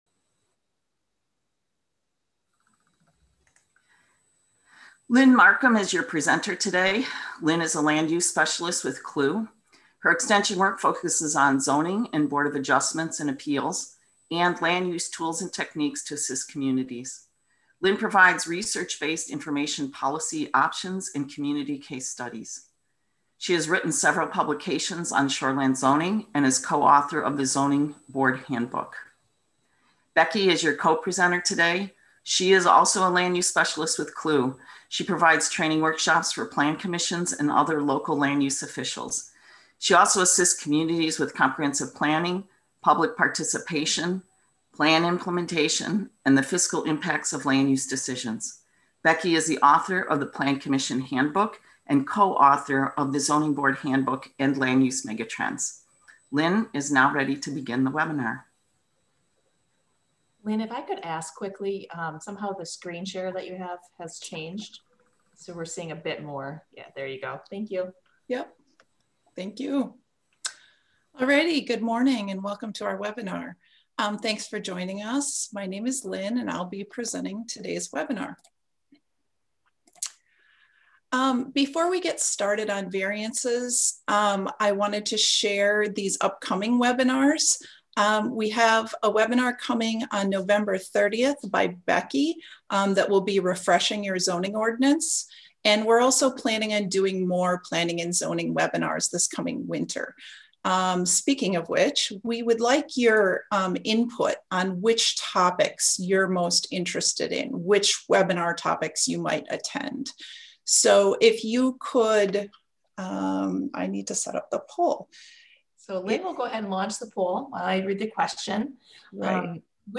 Webinar Materials